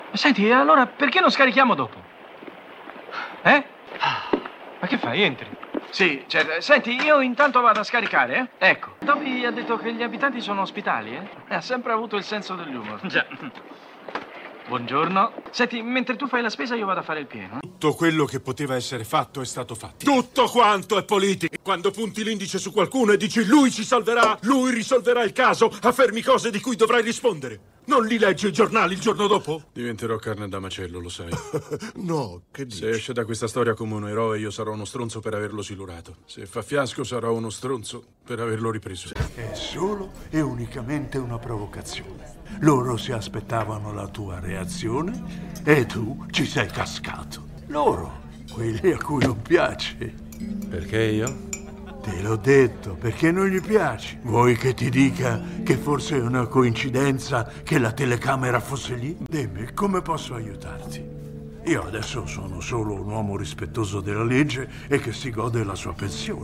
voce di Manlio De Angelis nel telefilm "Starsky & Hutch", in cui doppia Paul Michael Glaser, e nei film "Un detective... particolare", in cui doppia Harvey Keitel, e "Rampart", in cui doppia Ned Beatty.